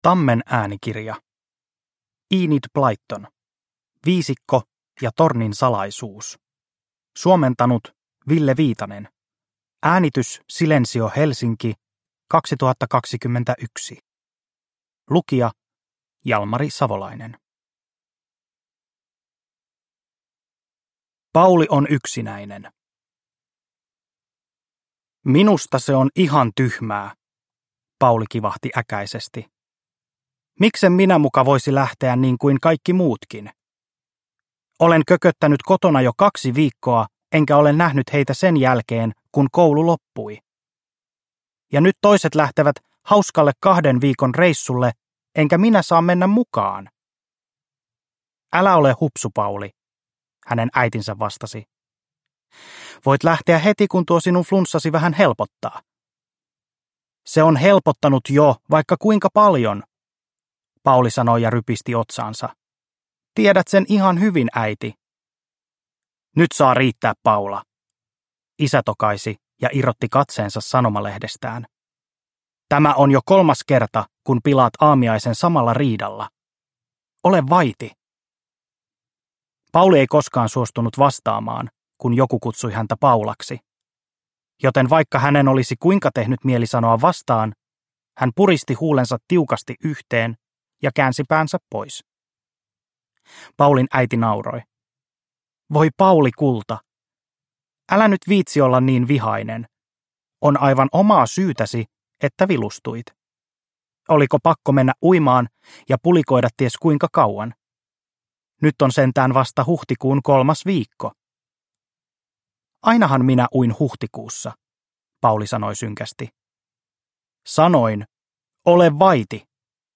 Viisikko ja tornin salaisuus – Ljudbok – Laddas ner